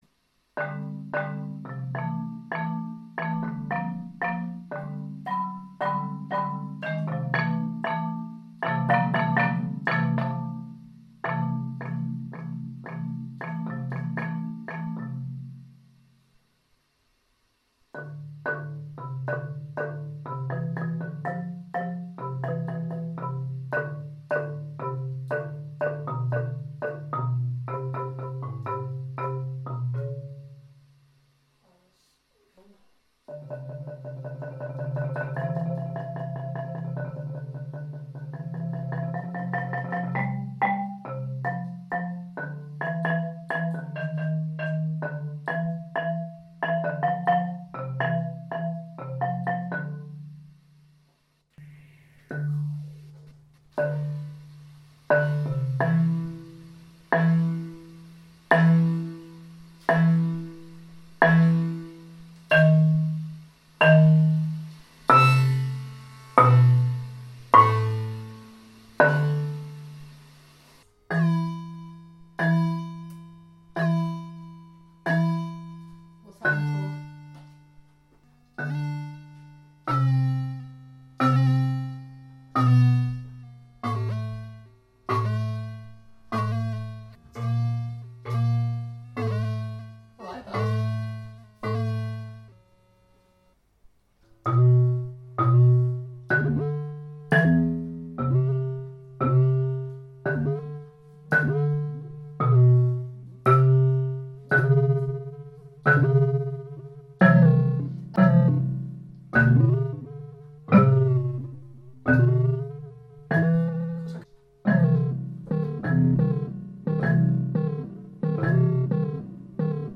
Playing with marimba & FX